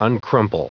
Prononciation du mot uncrumple en anglais (fichier audio)
Prononciation du mot : uncrumple
uncrumple.wav